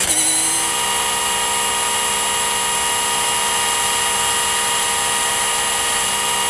rr3-assets/files/.depot/audio/sfx/transmission_whine/trans_on_high.wav
trans_on_high.wav